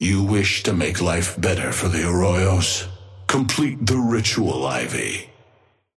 Amber Hand voice line - You wish to make life better for the Arroyos? Complete the ritual, Ivy.
Patron_male_ally_tengu_start_01.mp3